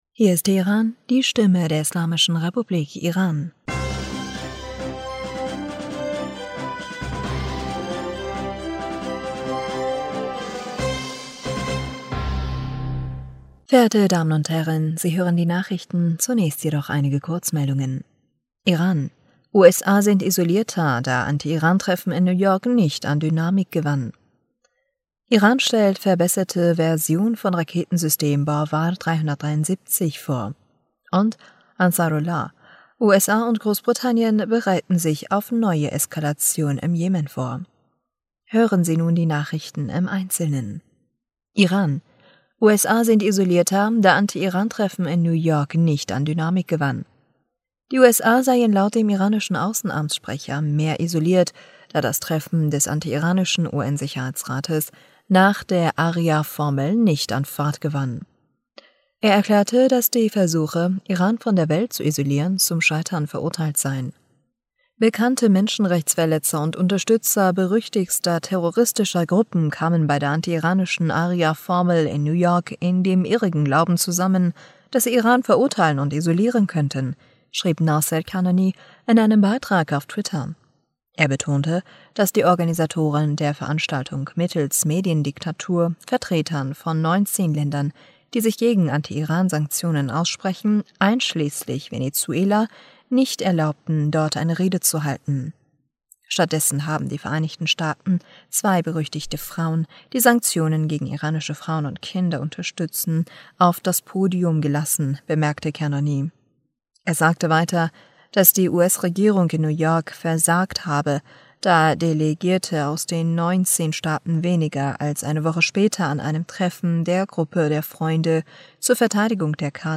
Nachrichten vom 7. November 2022